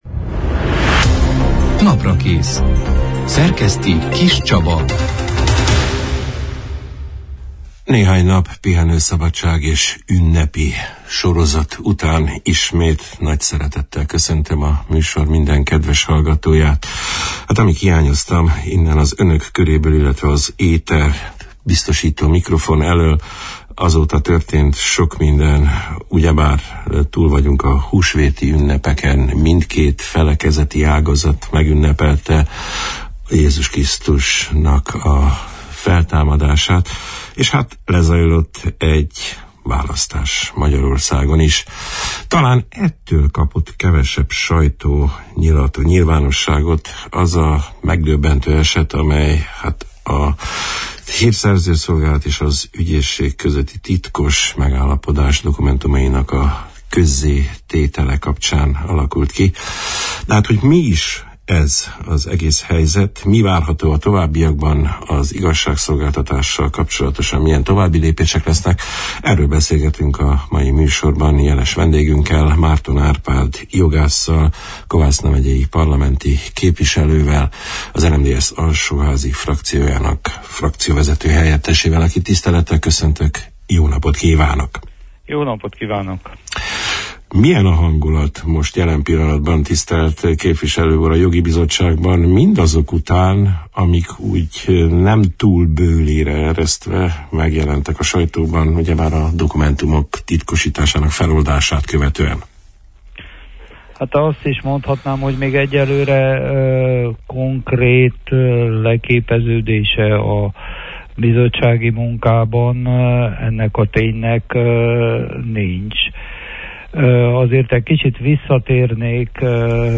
A titkosítás alól feloldott egyezmények tartalmáról, ezek esetleges hatásairól, a “magyar ügyek” értelmezésére, az ismét alkotmánybírósághoz került igazságügy törvénycsomag sorsáról, néhány érdekes törvényhozási kezdeményezésről, a hétfői újabb magyarellenes megnyilvánulással kapcsolatos további lépésekről beszélgettünk az április 11 – én, szerdán elhangzott Naprakész műsorban Márton Árpád jogásszal, Kovászna megyei parlamenti képviselővel, frakcióvezető – helyettessel.